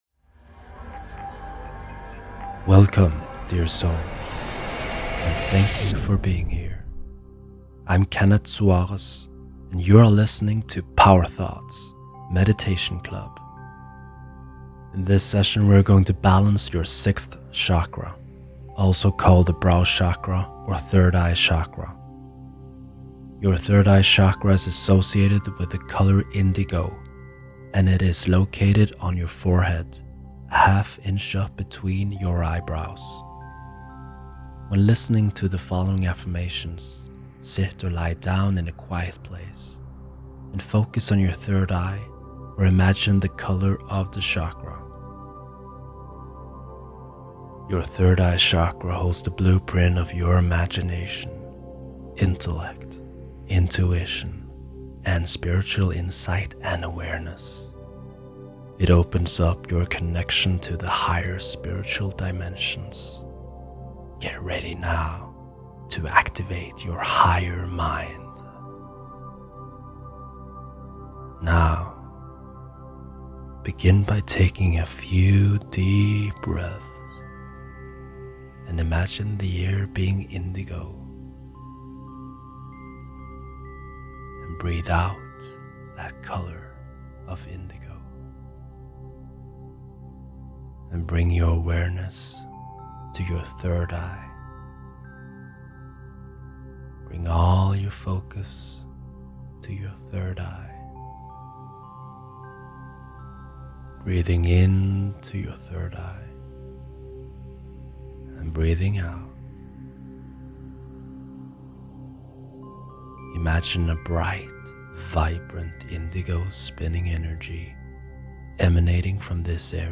Mixed with Solfeggio 852 hz and theta binaural beats to enhance the healing. The intention of this session is to balance your Sixth chakra, also called the brow chakra or third eye chakra.
6ActivatingQiFlowOfThirdEyeChakraMeditationEN.mp3